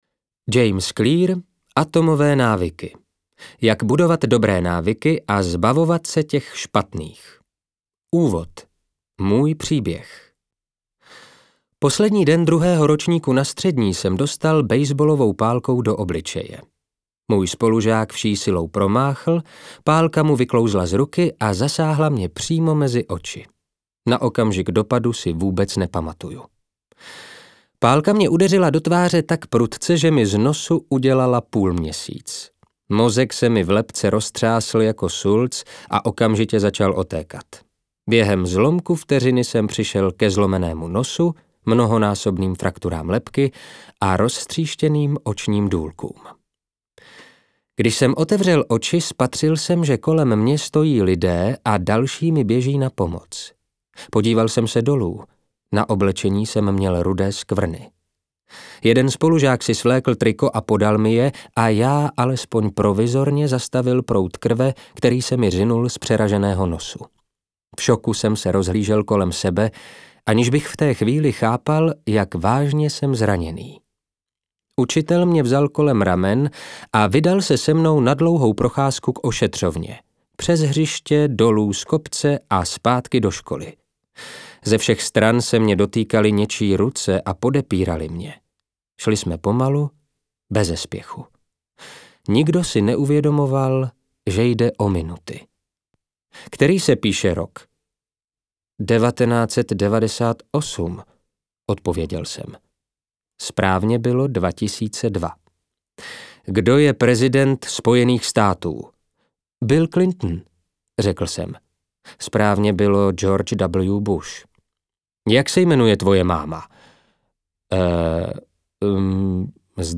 Audioknihy: